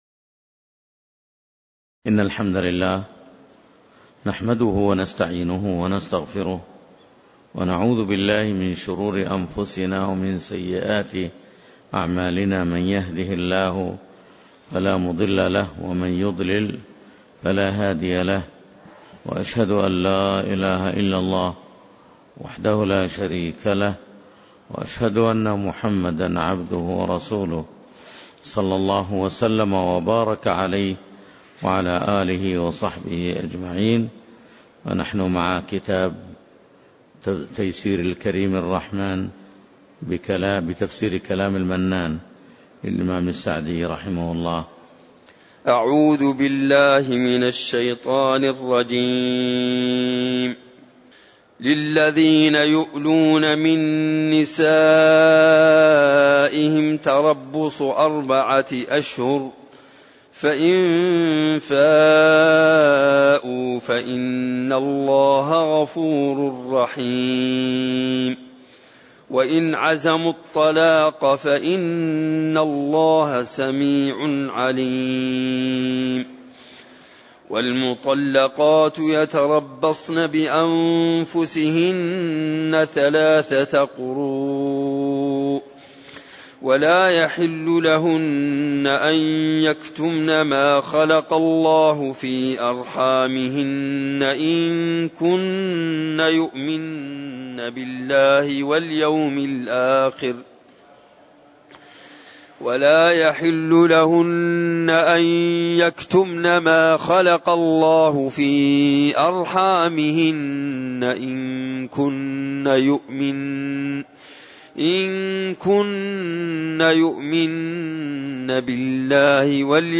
تاريخ النشر ٢٢ صفر ١٤٣٩ هـ المكان: المسجد النبوي الشيخ